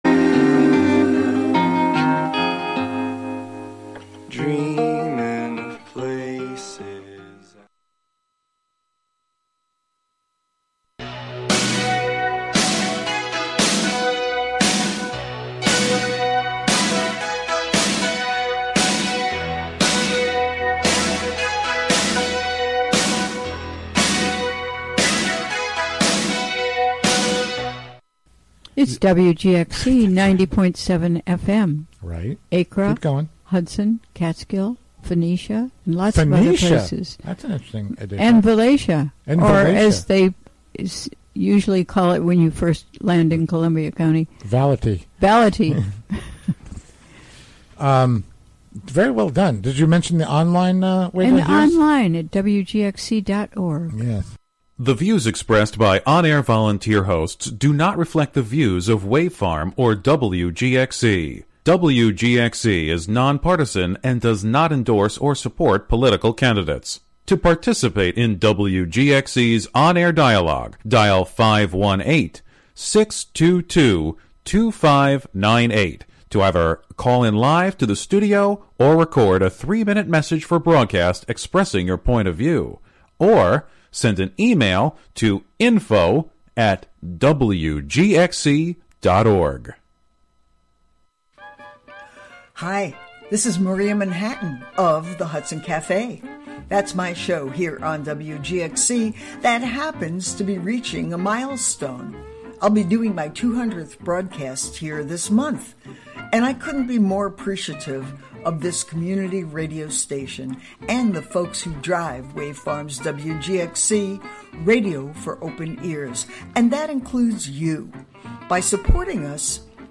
Hosted by various WGXC Volunteer Programmers.